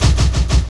15DR.BREAK.wav